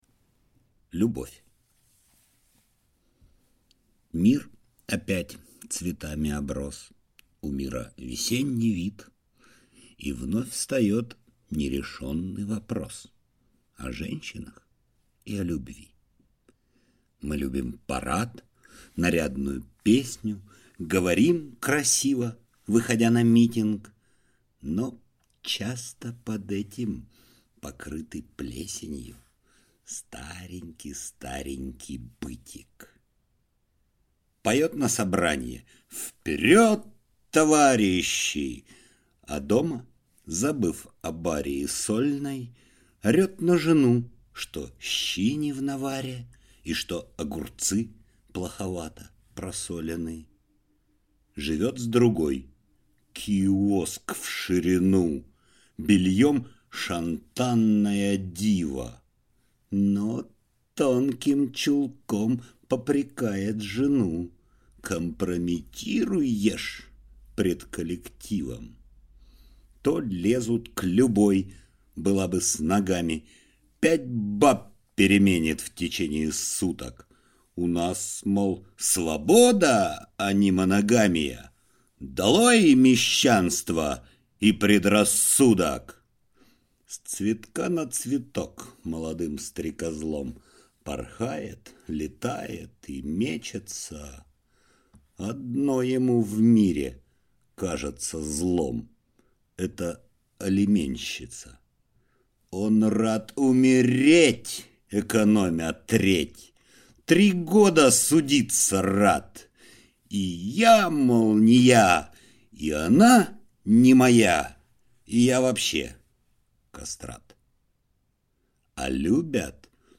Аудиокнига О любви | Библиотека аудиокниг